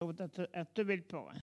Collectif atelier de patois